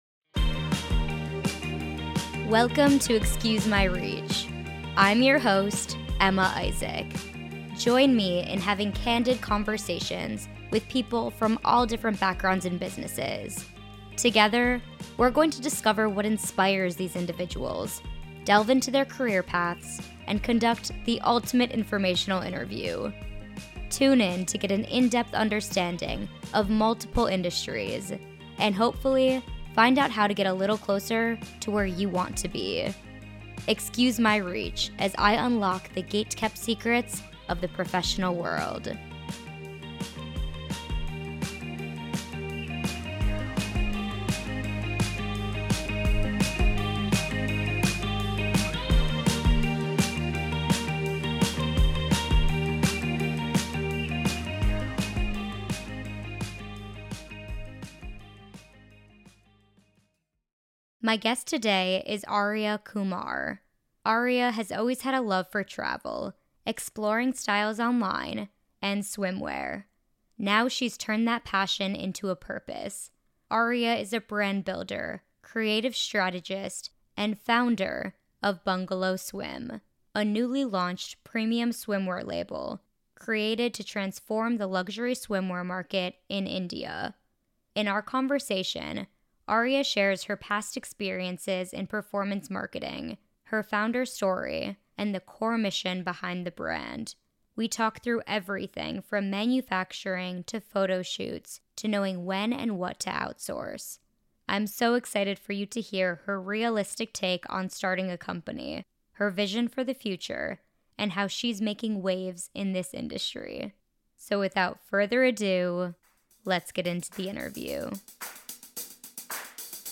Join me in having candid conversations with professionals from all different backgrounds and businesses. Together, we're going to discover what inspires these individuals, delve into their career paths, and conduct the ultimate informational interview.